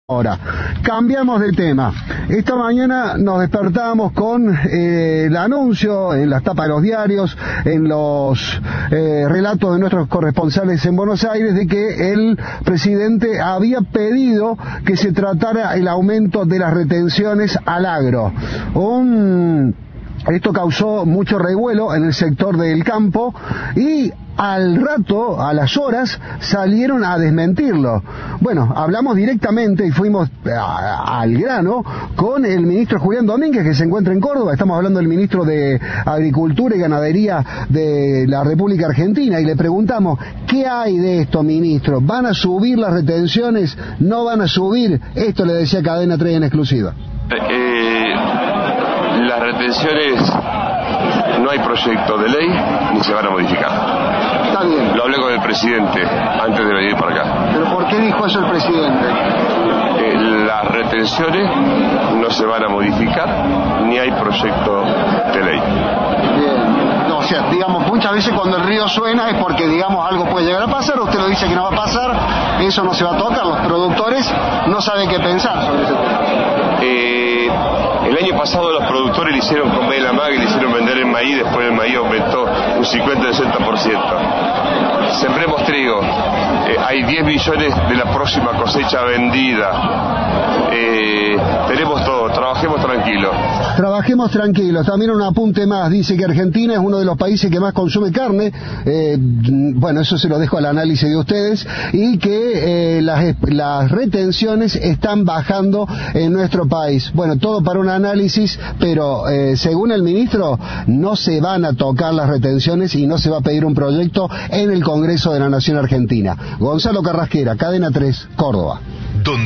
"De ninguna manera se van aumentar las retenciones ni enviar un proyecto de ley", dijo el ministro de Agricultura en declaraciones a Cadena 3.